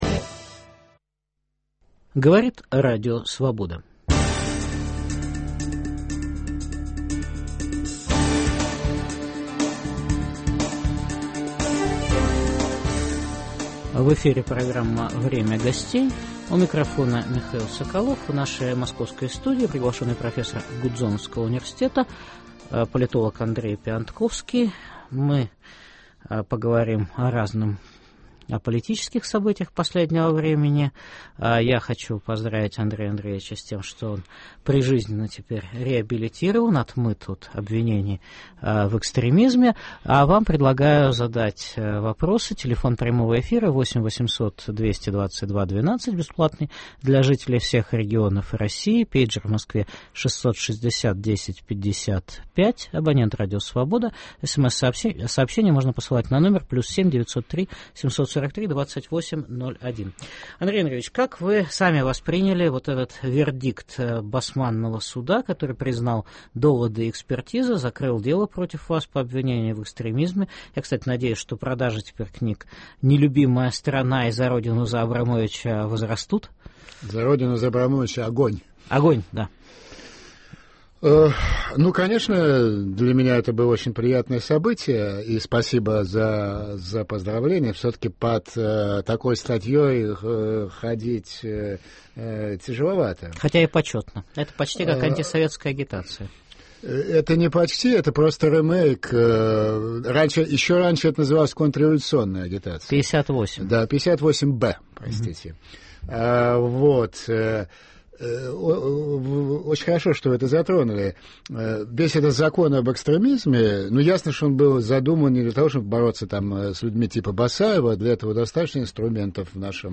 В программе выступит профессор Гудзоновского института в Вашингтоне политолог Андрей Пионтковский.